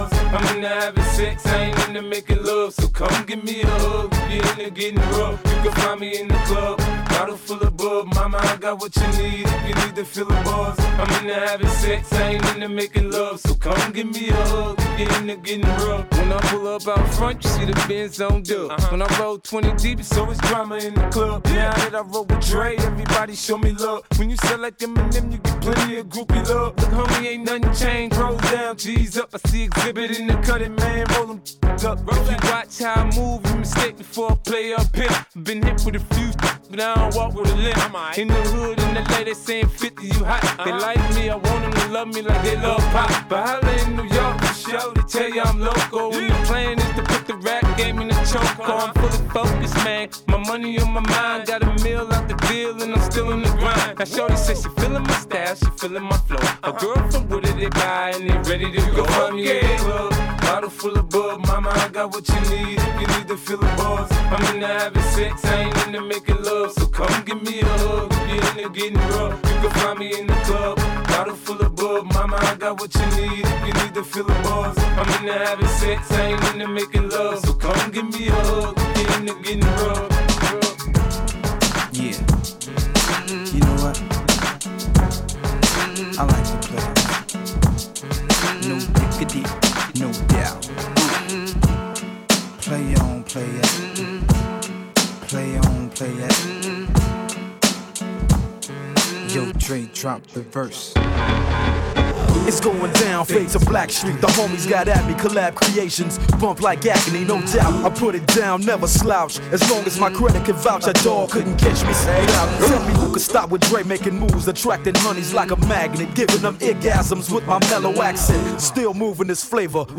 A live wedding mix